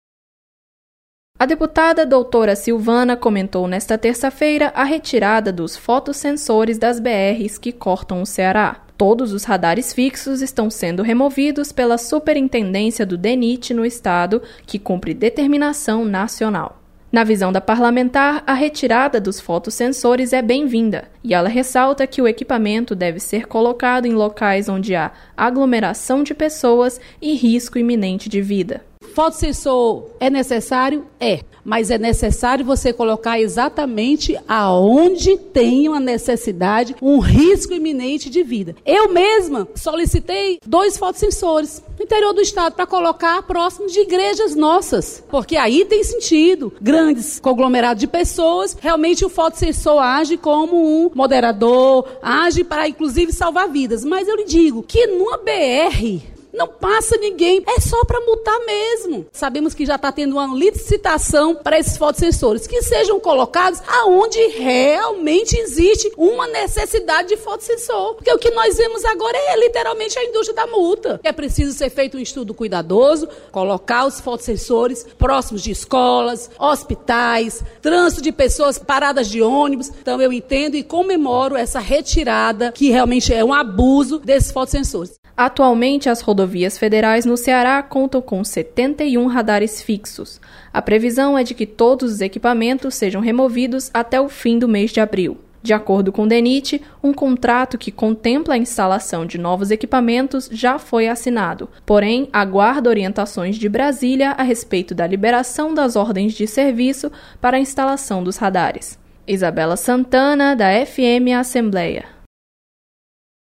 Deputada comenta retirada de fotossensores de rodovias federais no Ceará.